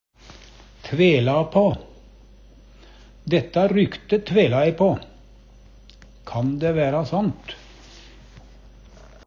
tvela på - Numedalsmål (en-US)